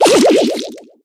bibi_ulti_hit_01.ogg